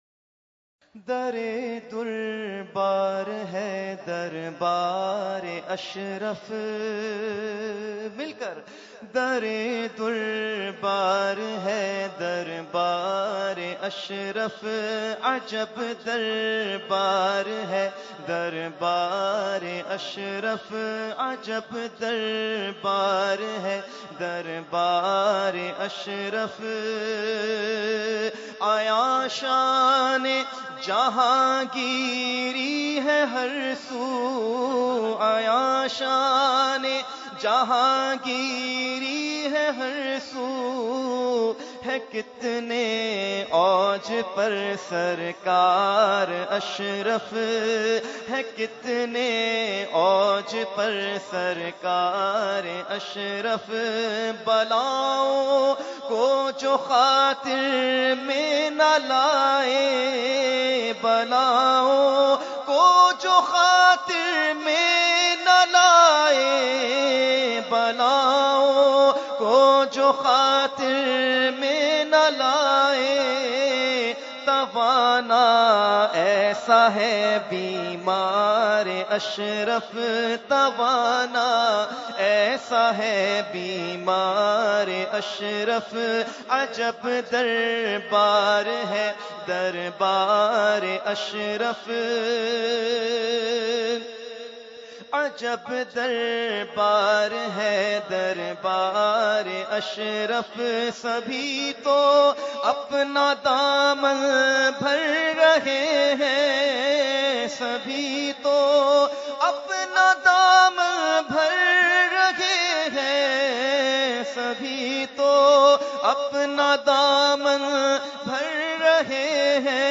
Category : Manqabat | Language : UrduEvent : Urs Makhdoome Samnani 2016